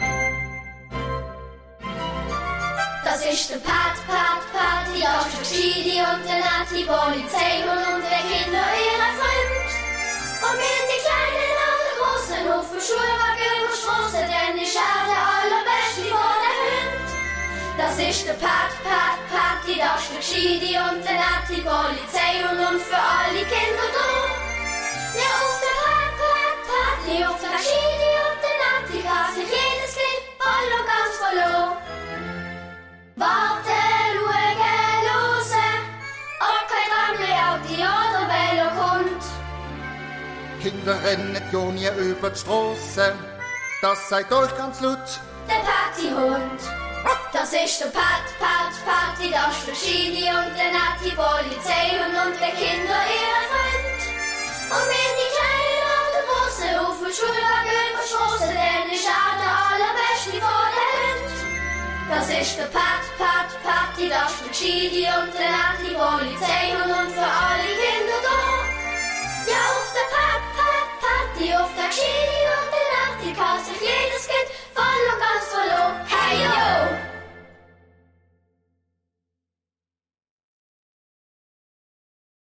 mit Text